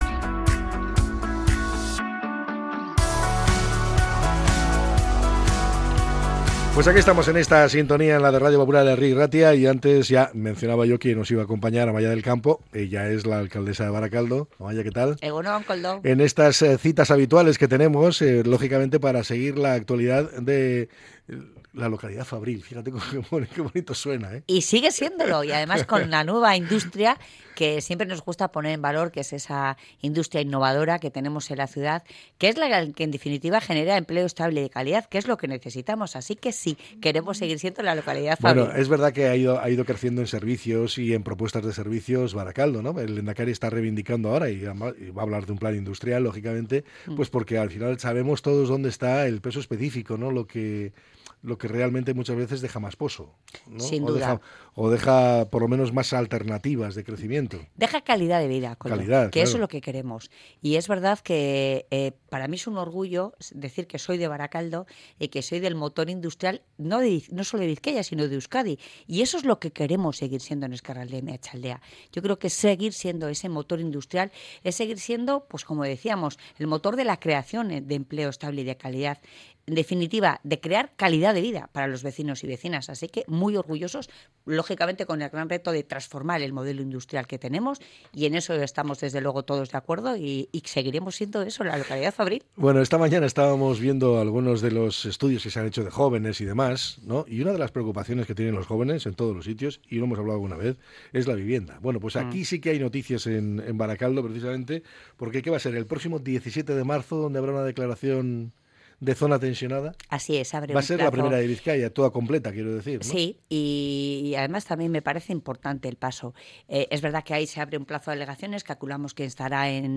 Entrevista con la alcaldesa de Barakaldo, Amaia del Campo